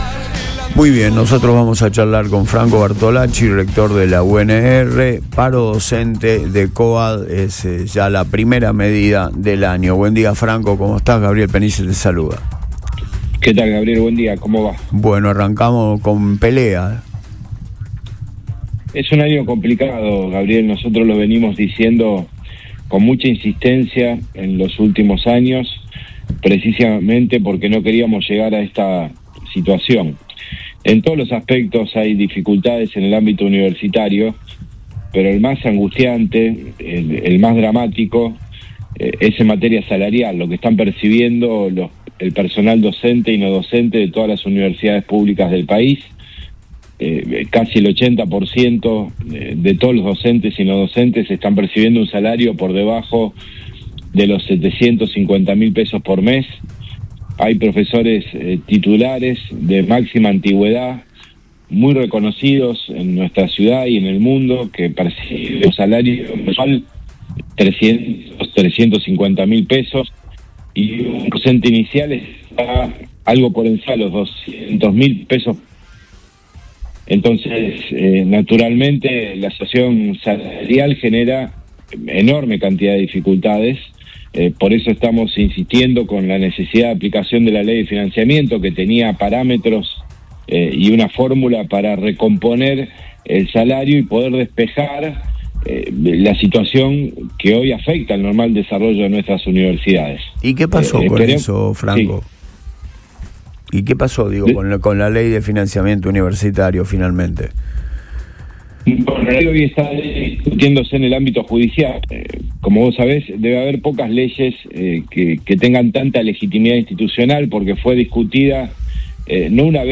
El rector de la Universidad Nacional de Rosario (UNR), Franco Bartolacci, pasó por el aire de Radio Boing en el inicio del ciclo lectivo 2026 y trazó un panorama desolador sobre la situación de los trabajadores de la educación superior.